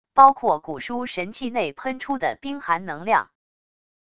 包括古书神器内喷出的冰寒能量wav音频